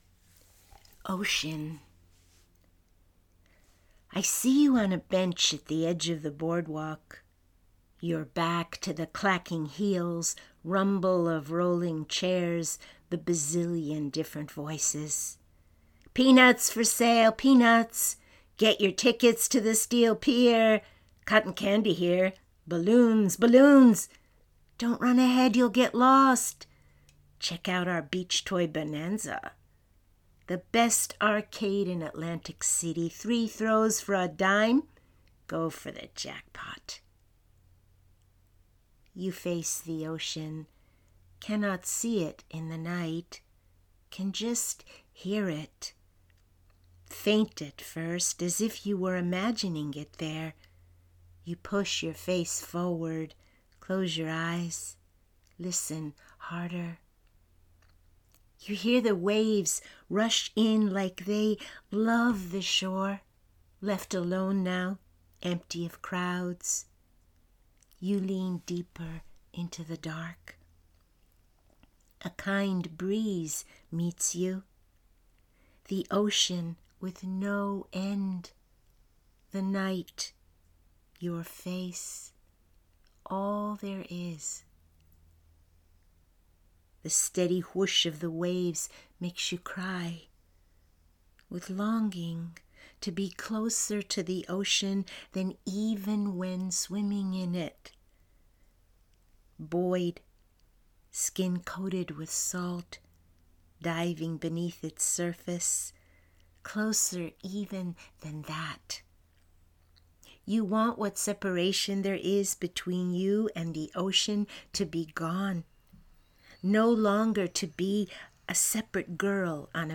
One of the vignettes in my forthcoming memoir is called “OCEAN,” which I share today with a recording of me reading “OCEAN” to you.